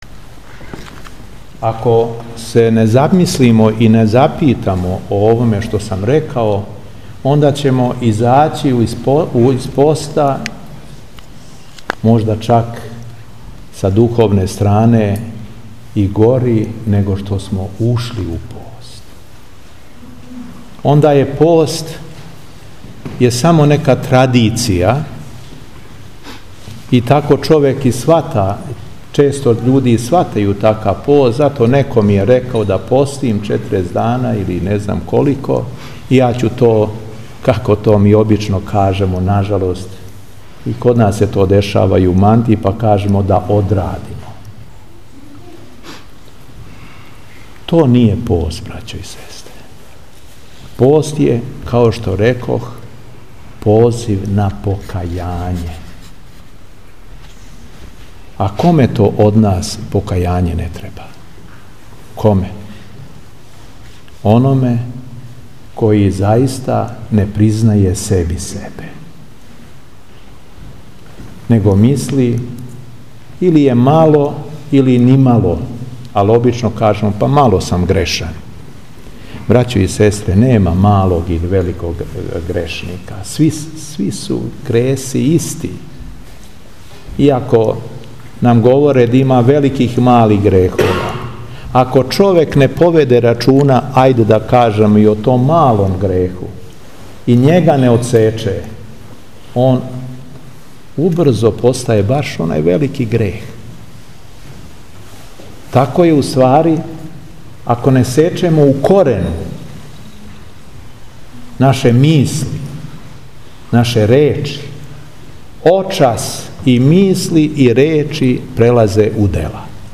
Беседа Епископа шумадијског Г. Јована
У уторак 20. марта 2018. године, Епископ шумадијски Г. Јован служио је Литургију Пређеосвећених дарова у храму Свете Тројице у крагујевачком насељу Корићани.